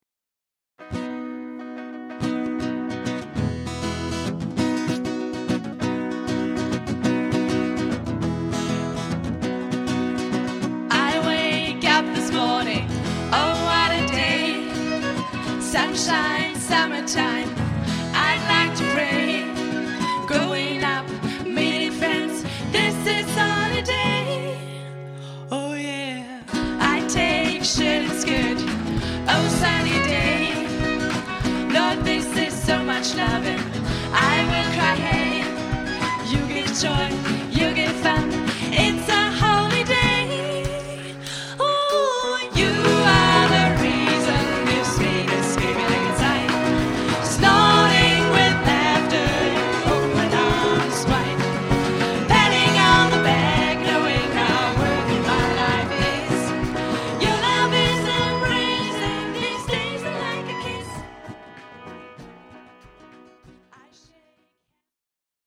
Live-Aufnahme des Konzerts 2008.